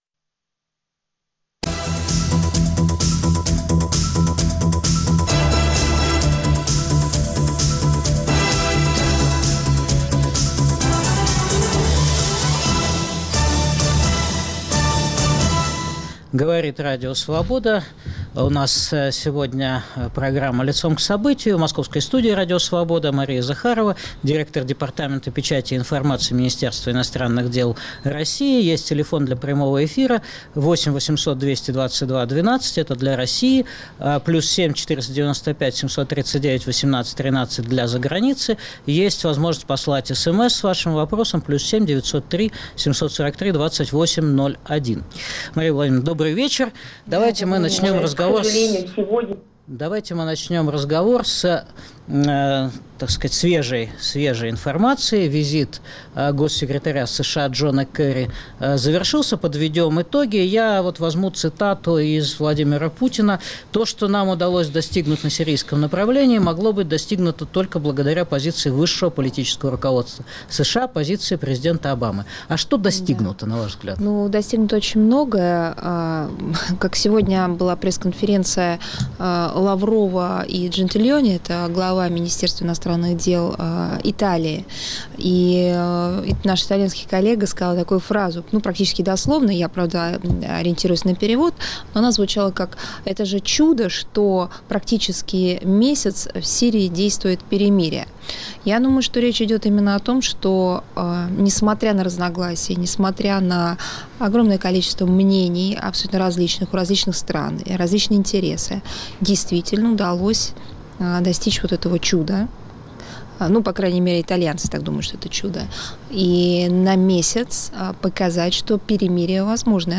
Официальный представитель МИД РФ Мария Захарова ответила на вопросы слушателей Радио Свобода.